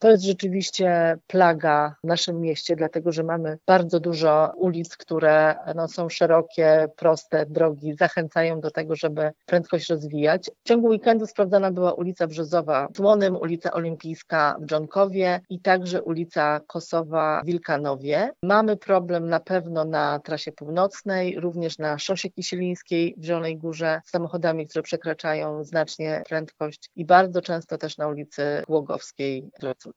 Specjalna platforma pozwala na zgłaszanie takich miejsc dodaje, rzeczniczka: